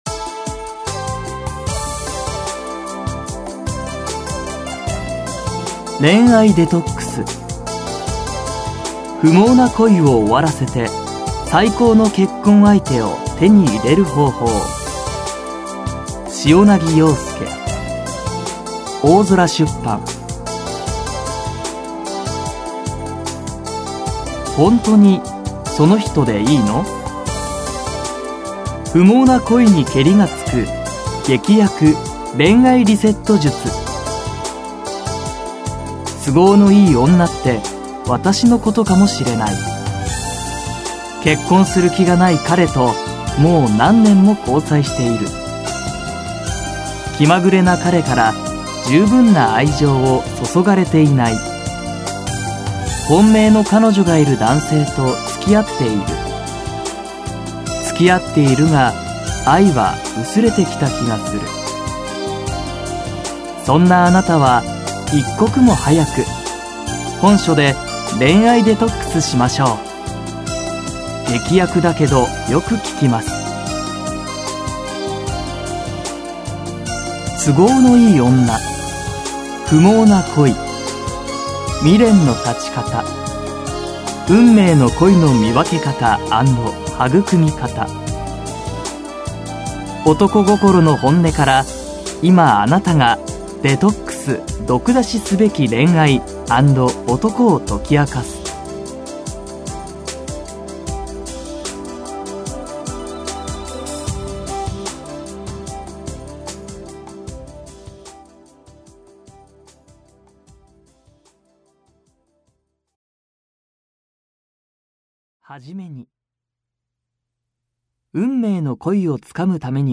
[オーディオブック] 恋愛デトックス